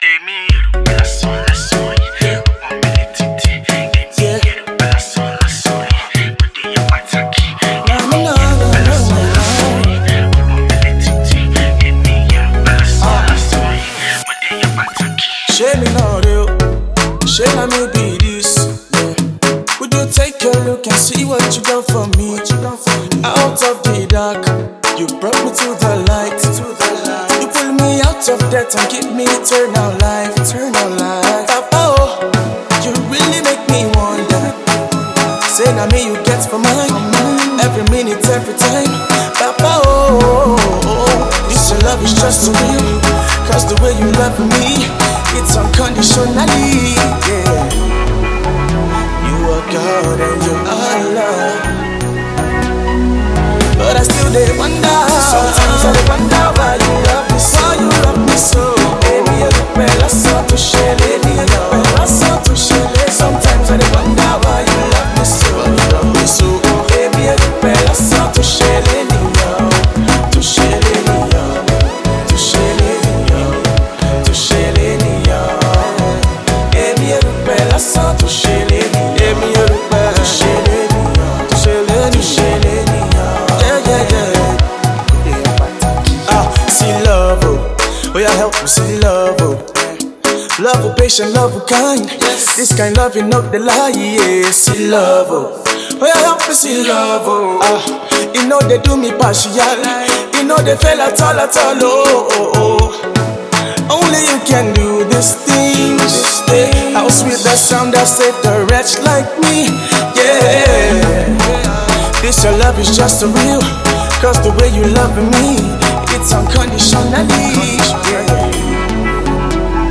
Afro/Reggae/Soul fusion artiste
groovy worship song